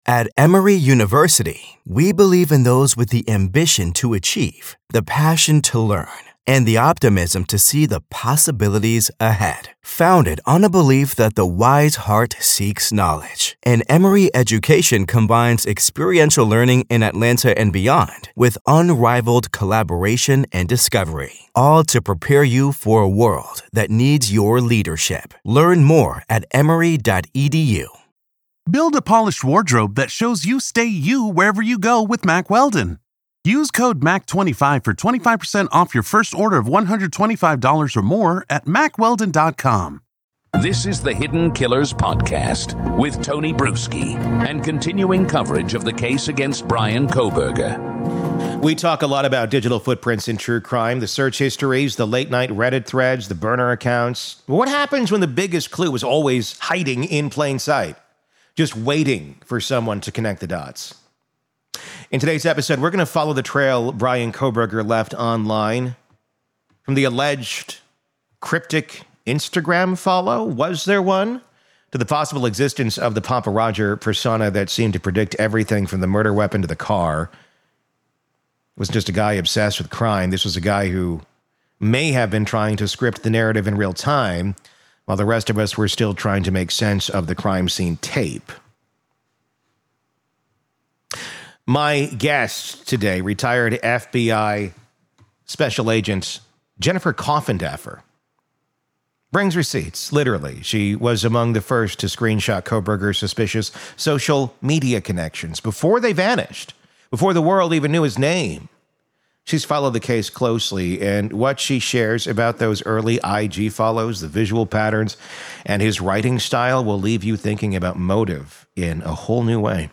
This episode unpacks what a Kohberger prison narrative might look like—and whether victims’ families would ever get justice if he tried to profit from his crimes. It’s a dark, speculative discussion—but one we need to have, before the publishing world gets ahead of accountability.